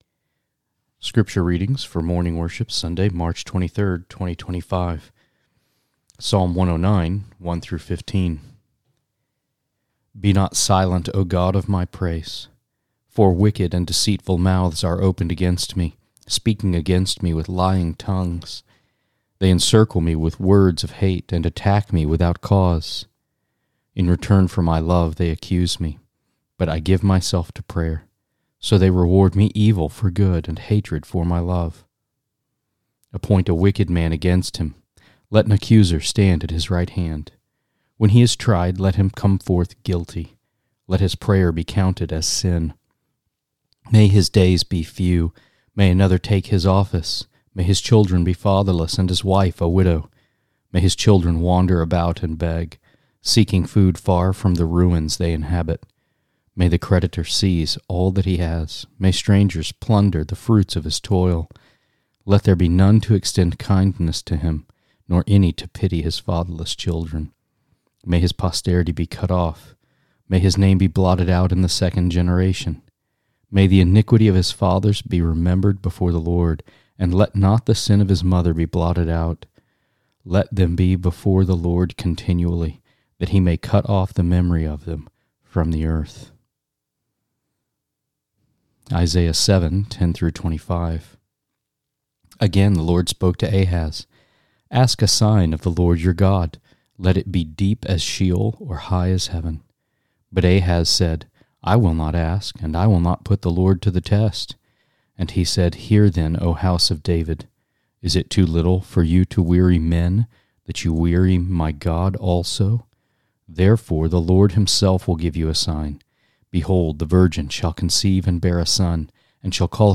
Sermons and Lessons from All Saints Presbyterian Church (PCA) in Brentwood, TN.